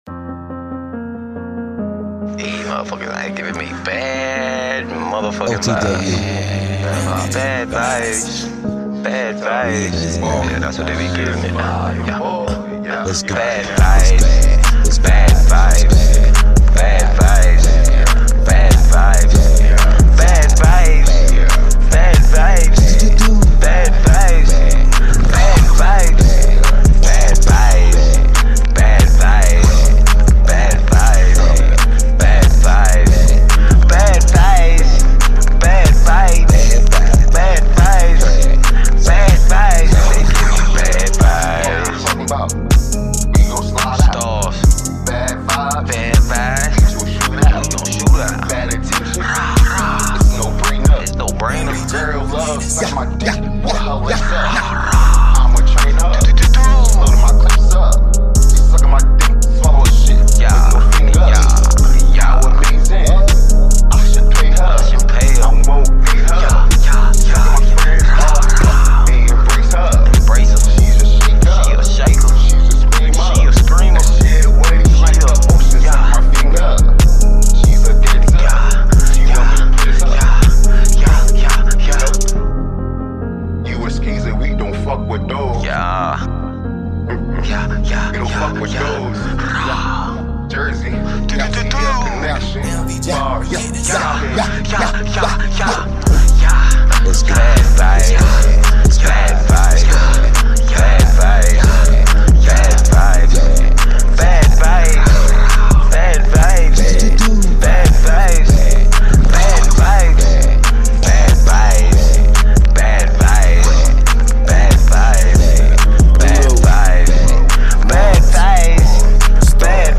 Quality of sound may not be 💯 but respect the grind.